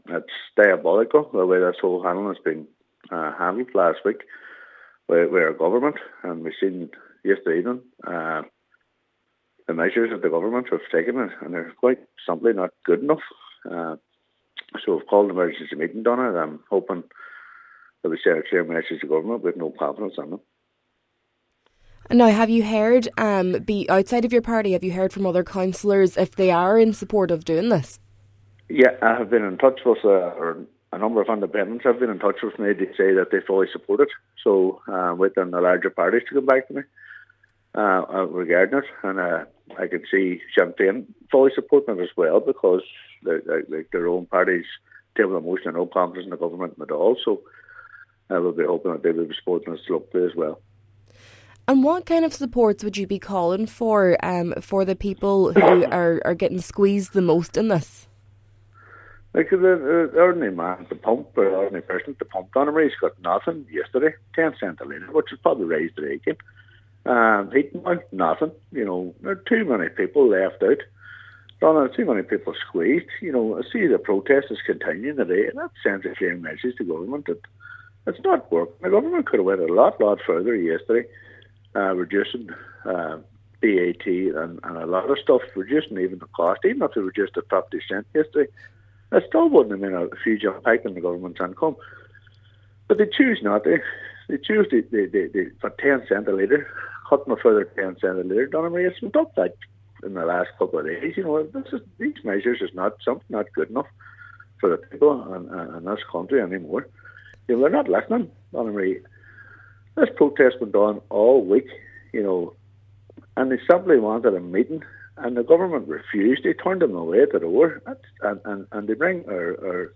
Cllr Devine says for many, it’s heat or eat: